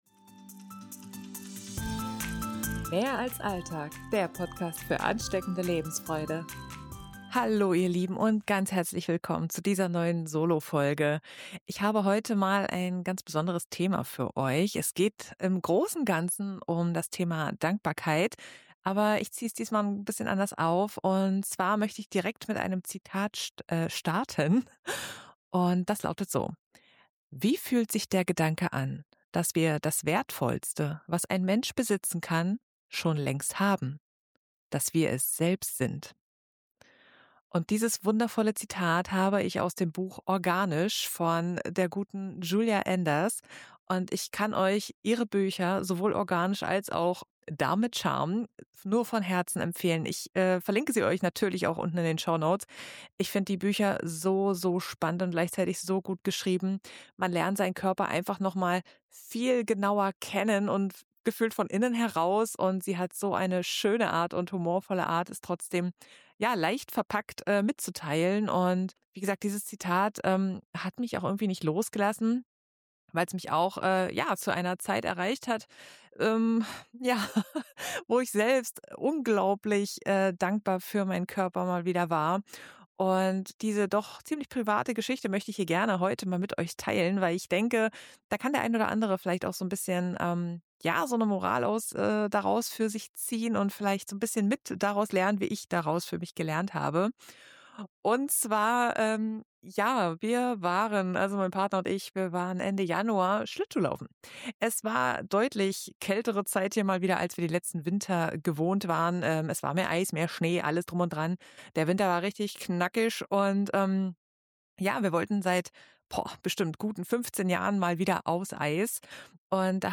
In dieser sehr persönlichen Solofolge teile ich eine Geschichte aus meinem Leben, die mich wieder einmal mehr gelehrt hat, wie unfassbar dankbar ich für meinen Körper sein kann und wie enorm wichtig es ist, viel mehr im Hier und Jetzt zu sein, statt mit den Gedanken zu sehr abzuschweifen aber hört selbst!